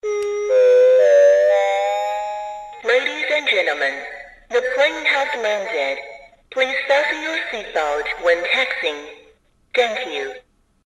voice over PA - toy airplane.mp3
Recorded with a Steinberg Sterling Audio ST66 Tube, in a small apartment studio.
voice_over_pa_-_toy_airplane_jh5.ogg